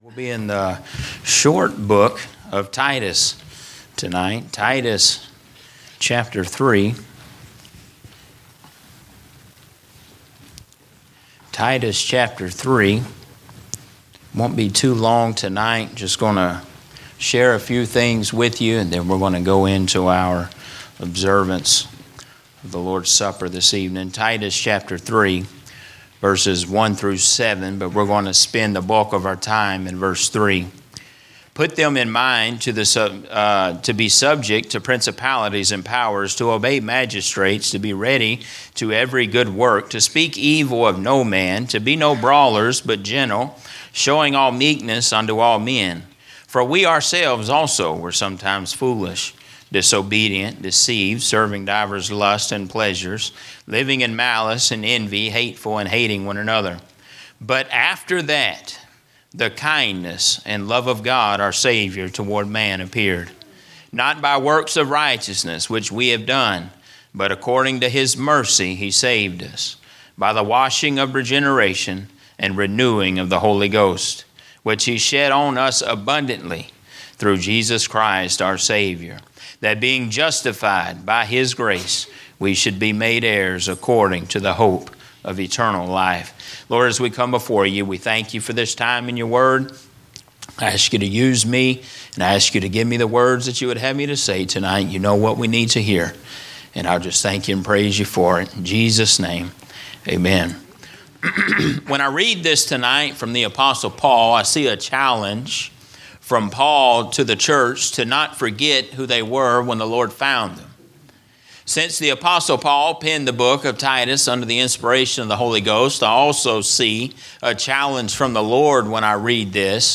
Sermons - Emmanuel Baptist Church